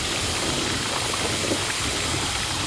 poison_loop_01.wav